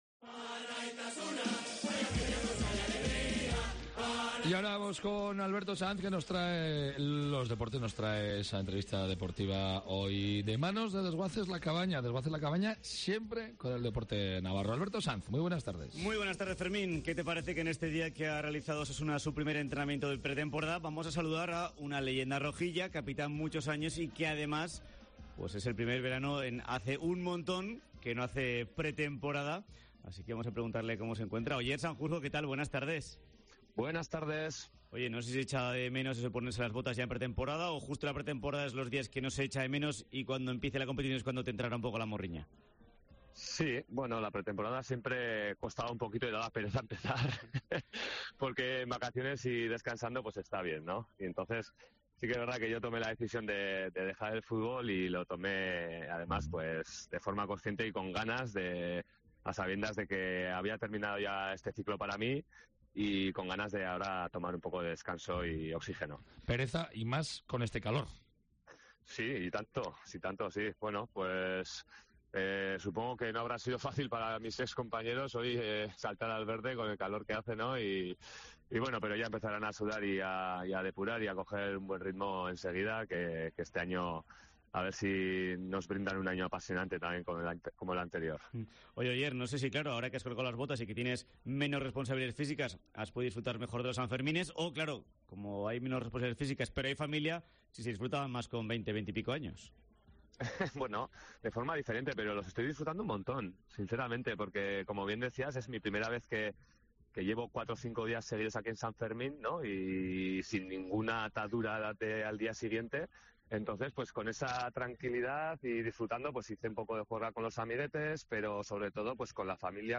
Entrevista deportiva, con Desguaces La Cabaña
Oier Sanjurjo habla en Cope Navarra de los primeros sanfermines que vivirá después de retirarse.